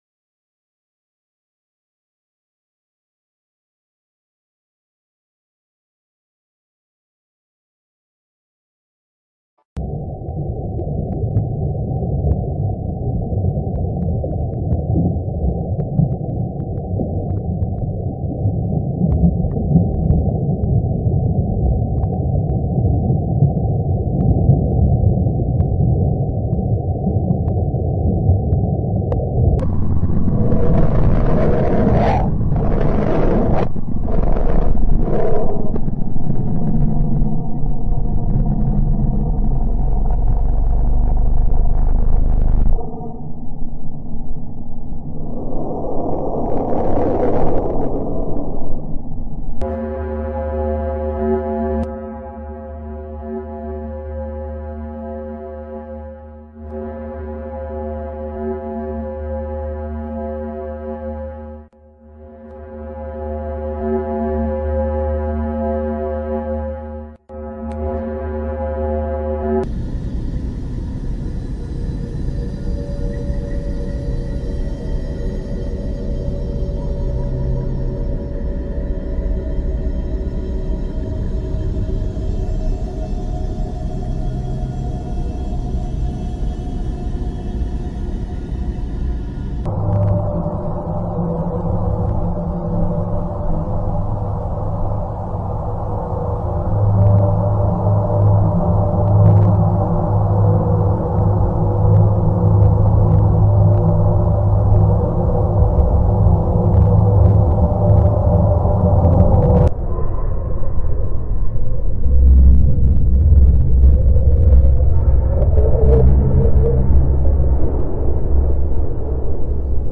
Attiva la traccia audio per vivere l'esperienza immersiva della mostra grazie ai suoni dei pianeti del Sistema Solare.
Il-suono-dellUniverso-lo-straordinario-canto-dei-pianeti-del-Sistema-Solare-VIDEO-HD.mp3